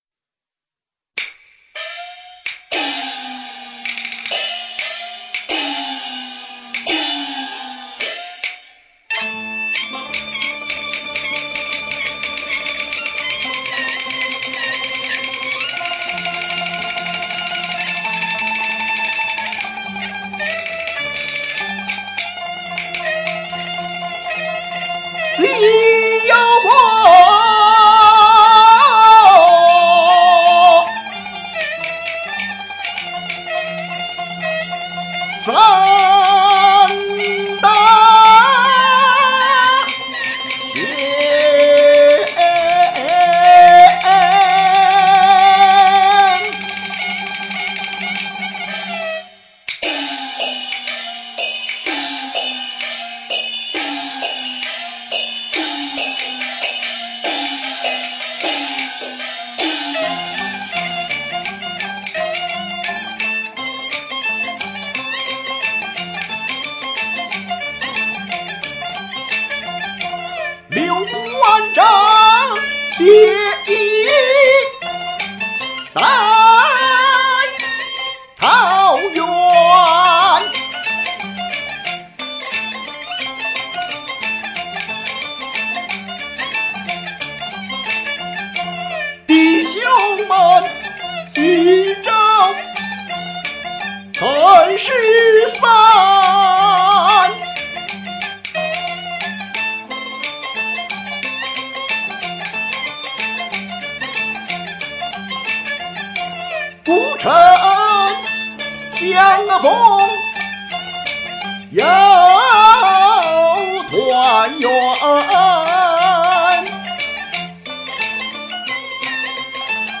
京剧《珠帘寨》
很有力道！
醇厚有力道，够味儿！